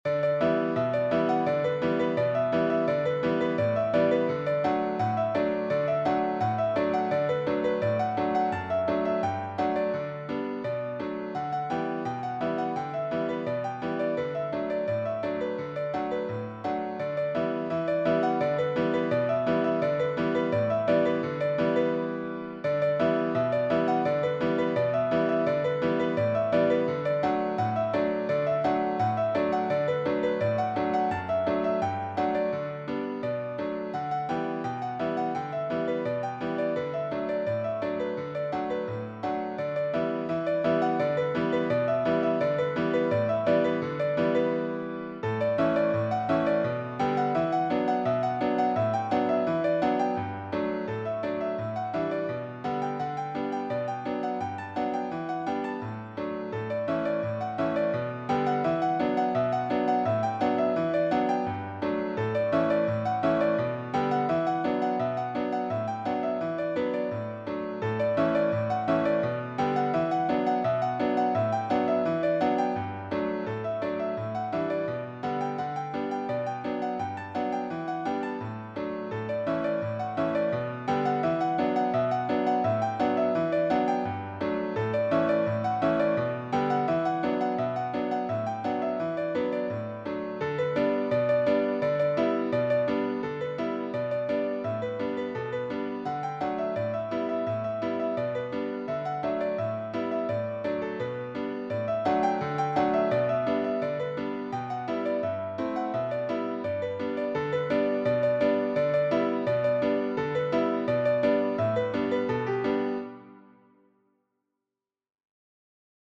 a lively ragtime piece that captures the rhythmic pounding
Playful
Modern ragtime
Piano only